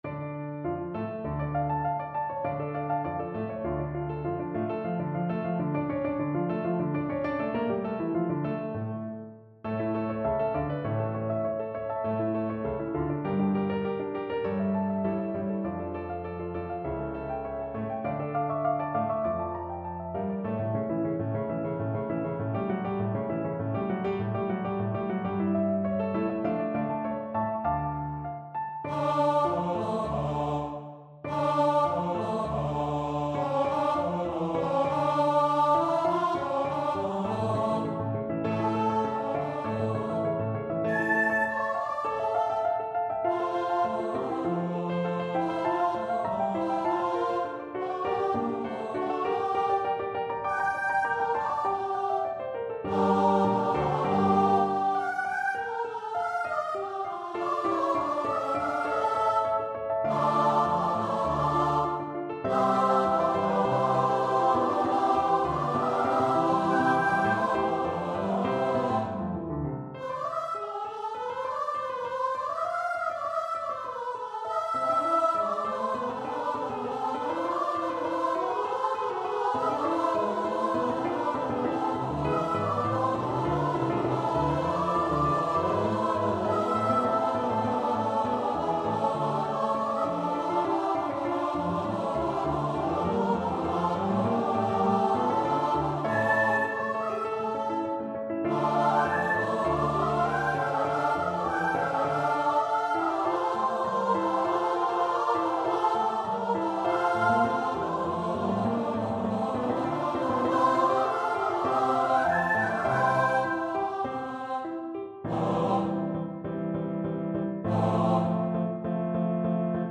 Fallen is the foe (Judas Maccabaeus) Choir version
Choir  (View more Intermediate Choir Music)
Classical (View more Classical Choir Music)